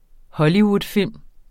Udtale [ ˈhʌliwud- ]